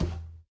minecraft / sounds / mob / irongolem / walk3.ogg
walk3.ogg